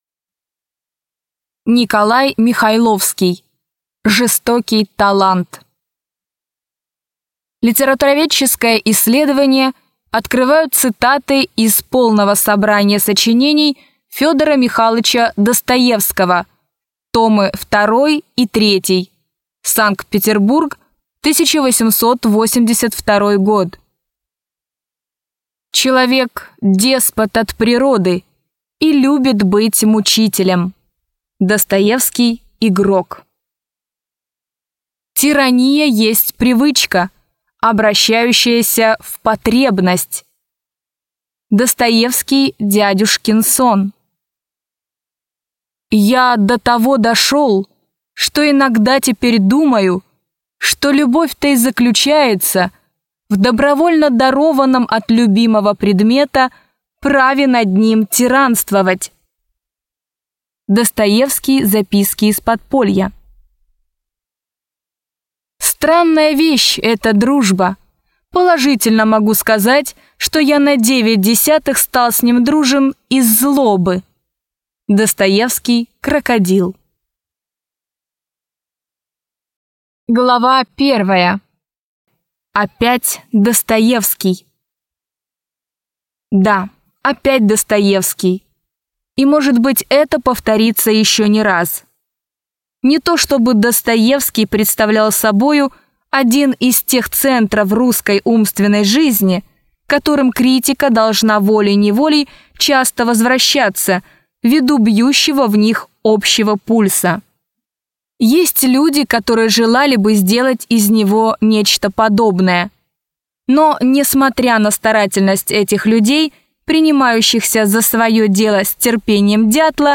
Аудиокнига Жизнь и творчество Федора Михайловича Достоевского | Библиотека аудиокниг